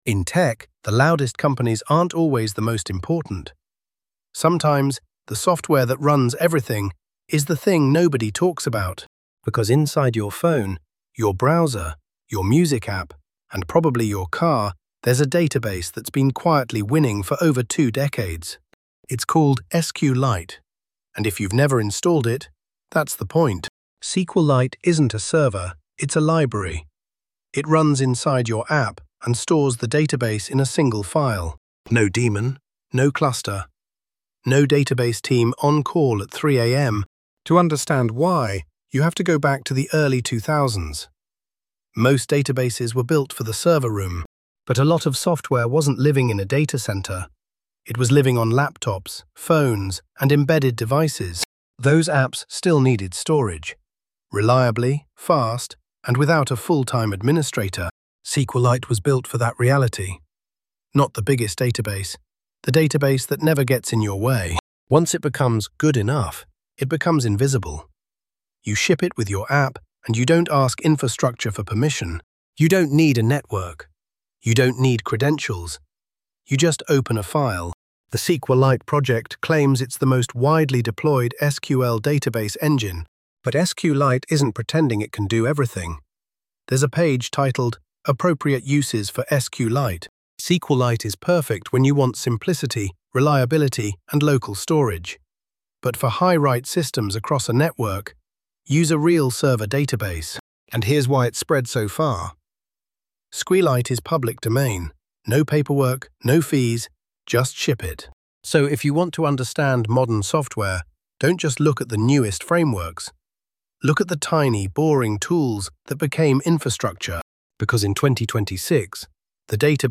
British voiceover variant of Episode 1 (SQLite), plus an ms-precision beat-aligned storyboard timeline JSON.
Voiceover-only (British)